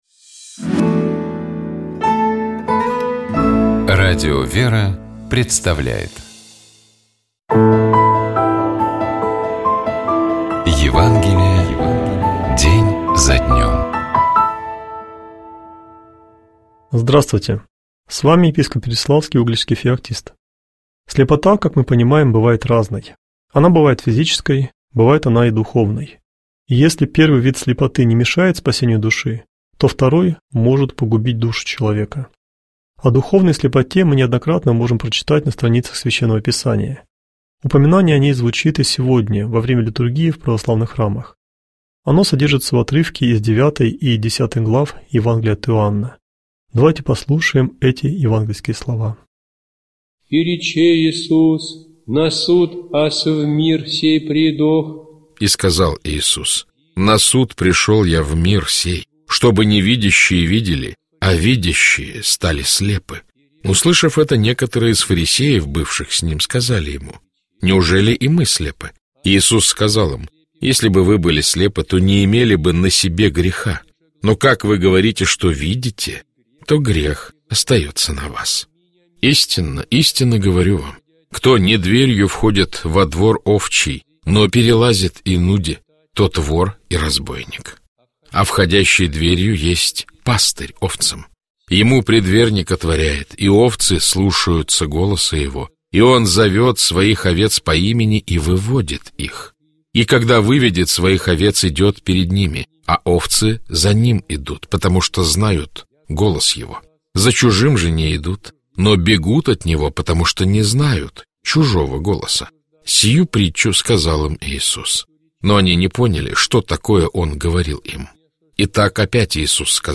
Читает и комментирует
епископ Феоктист ИгумновЧитает и комментирует епископ Переславский и Угличский Феоктист